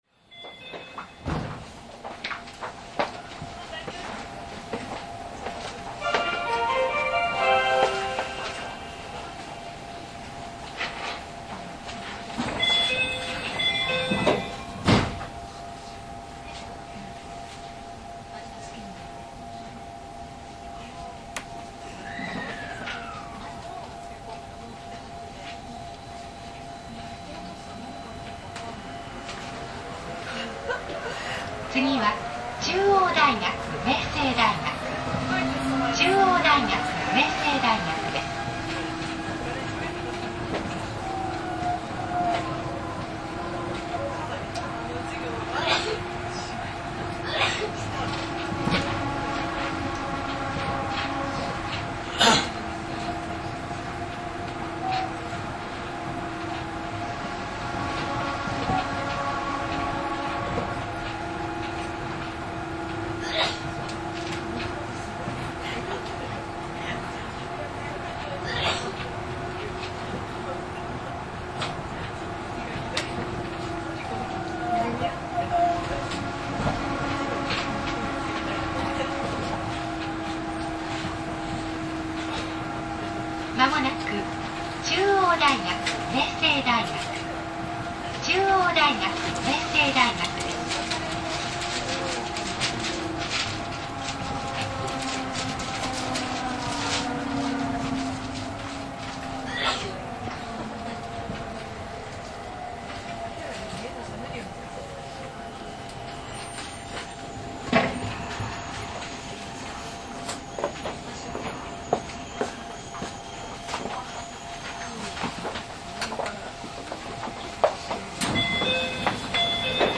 走行音
TM03 1000系 大塚・帝京大学→中央大学・明星大学 2:03 9/10 --